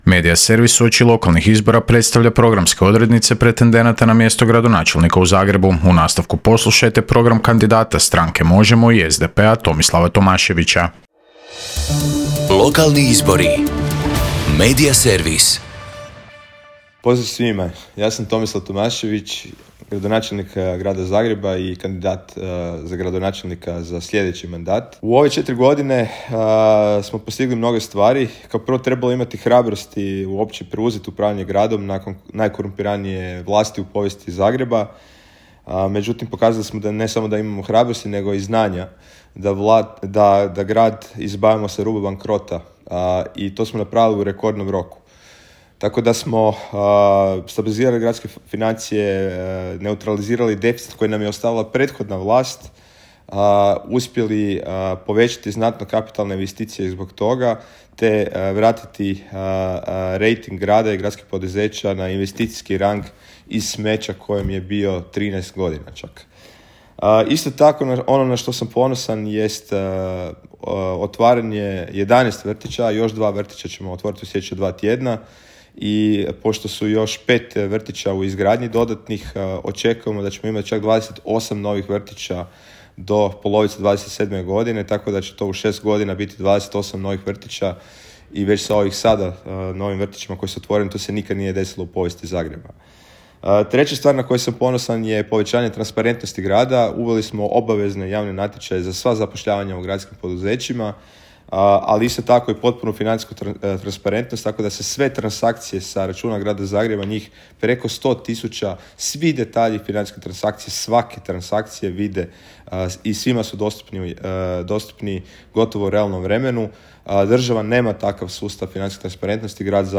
ZAGREB - Uoči lokalnih izbora kandidati za gradonačelnicu/gradonačelnika predstavljaju na Media servisu svoje programe u trajanju od pet minuta. Kandidat za gradonačelnika Zagreba ispred platforme Možemo! i SDP-a Tomislav Tomašević predstavio je građanima svoj program koji prenosimo u nastavku.